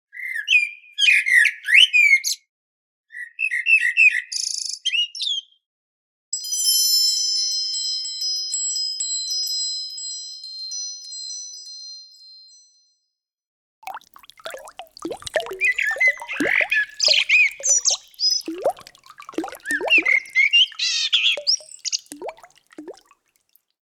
Zwitscherbox Lily Bells Selection, set van drie vogelgeluiden
Samen zorgen ze voor een natuurlijk en speels samenspel van vogelgeluiden, aangevuld met zachte belletjes en subtiele waterklanken.
Geluid merel, zanglijster en nachtegaal met zachte klankaccenten